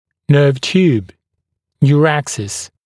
[nɜːv t(j)uːb] [ʧuːb] [nju’ræksɪs][нё:в т(й)у:б] [чу:б] [нйу’рэксис]нервная трубка